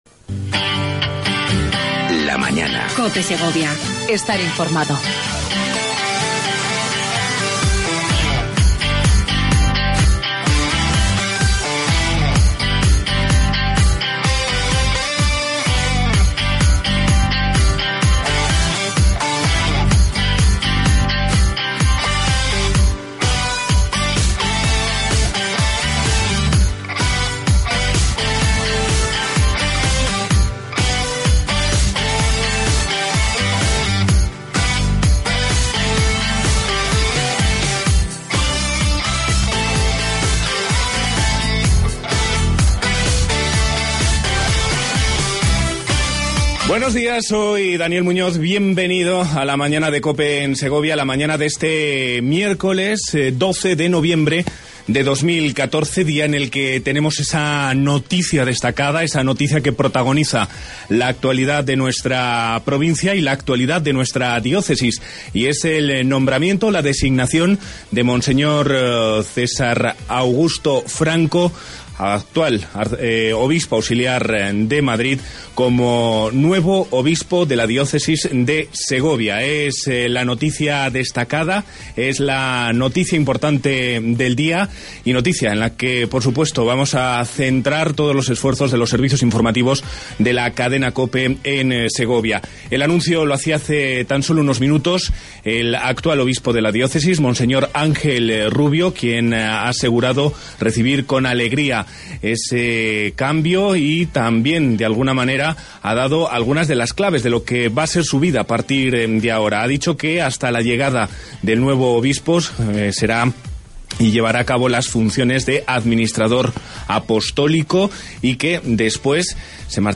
AUDIO: Entrevista con Monseñor César Franco, Obispo Electo de Segovia.